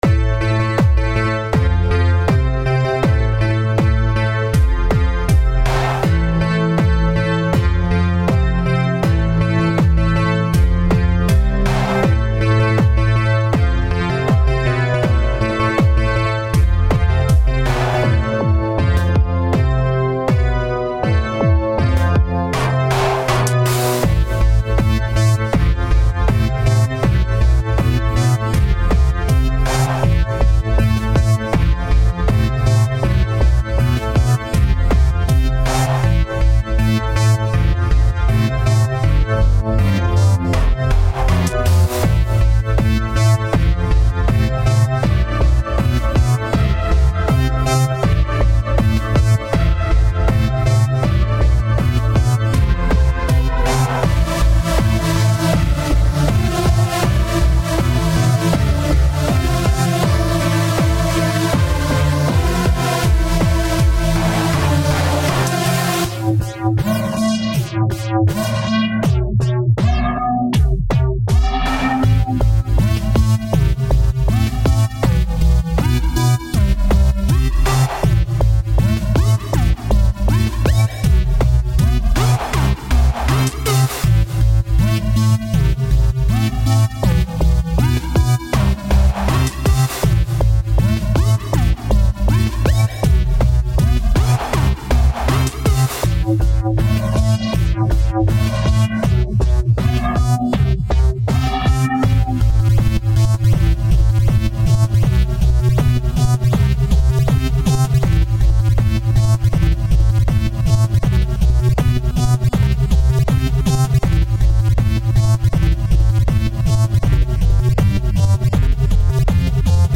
requiem